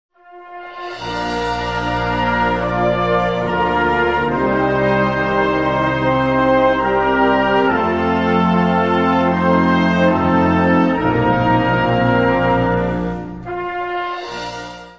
Kategorie Blasorchester/HaFaBra
Unterkategorie Konzertmusik
Besetzung Flexi (variable Besetzung)
Besetzungsart/Infos 5part; Perc (Schlaginstrument)